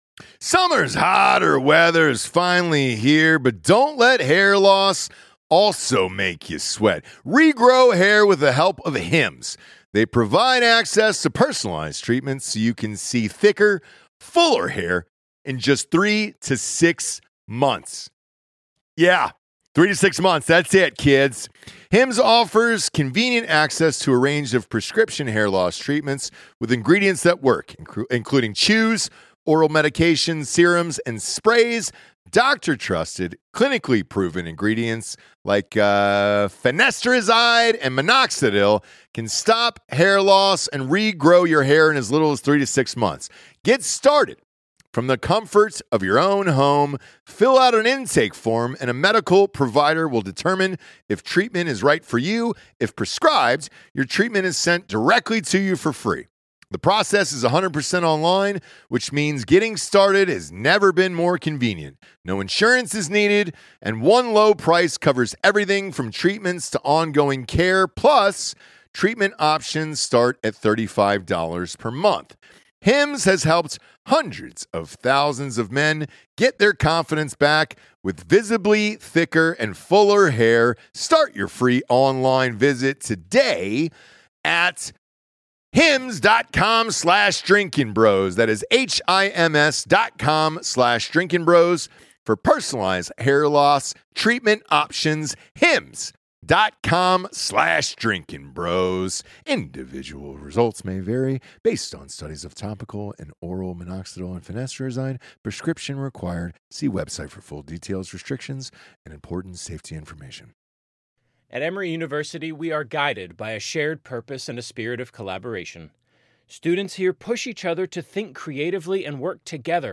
Episode 673 - Live From University Of Texas!